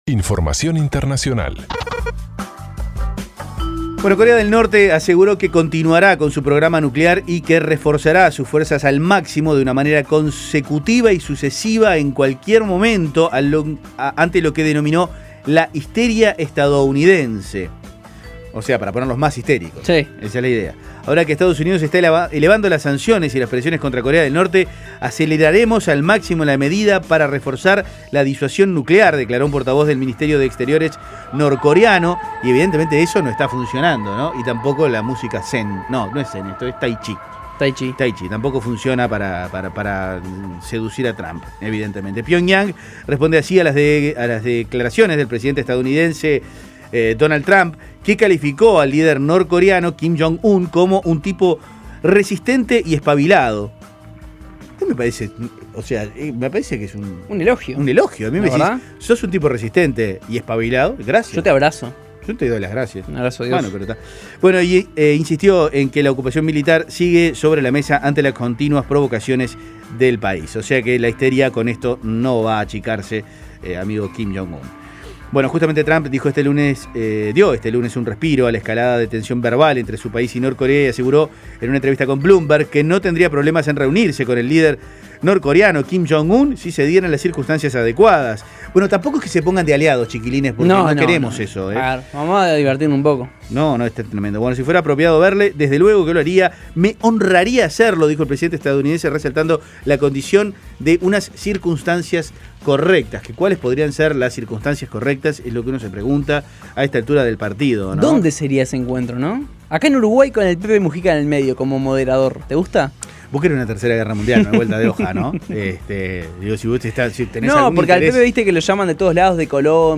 Resumen de noticias internacionales